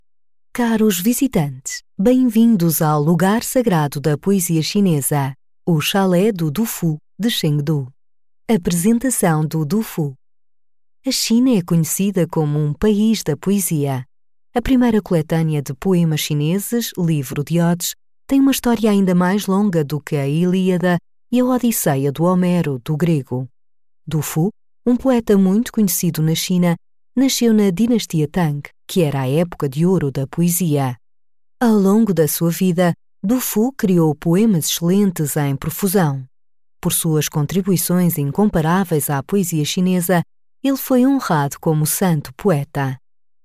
葡萄牙语翻译团队成员主要由中国籍和葡萄牙语国家的中葡母语译员组成，可以提供证件类翻译（例如，驾照翻译、出生证翻译、房产证翻译，学位证翻译，毕业证翻译、成绩单翻译、无犯罪记录翻译、营业执照翻译、结婚证翻译、离婚证翻译、户口本翻译、奖状翻译等）、公证书翻译、病历翻译、葡语视频翻译（听译）、葡语语音文件翻译（听译）、技术文件翻译、工程文件翻译、合同翻译、审计报告翻译等；葡萄牙语配音团队由葡萄牙语国家的葡萄牙语母语配音员组成，可以提供葡萄牙语专题配音、葡萄牙语广告配音、葡萄牙语教材配音、葡萄牙语电子读物配音、葡萄牙语产品资料配音、葡萄牙语宣传片配音、葡萄牙语彩铃配音等。
葡萄牙语样音试听下载